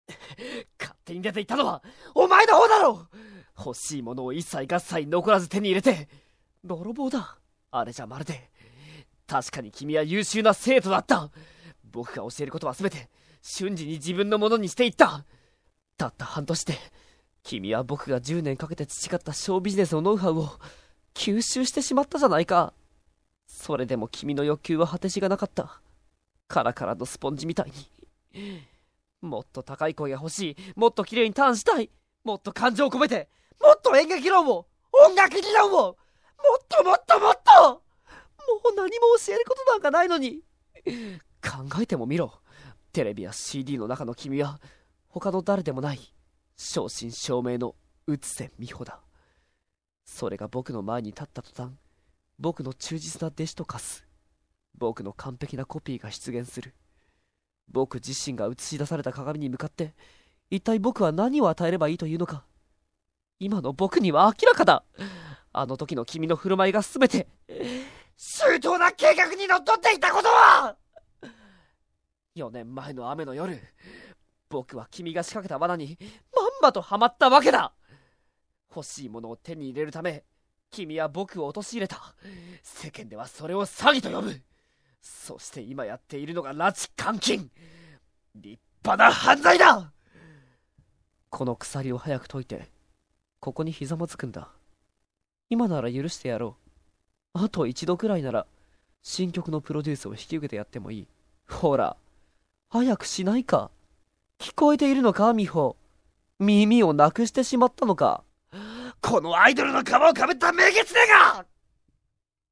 声真似奥義演習場~その弐~
声マネに関しては似てるモン似てねぇモン多々あります。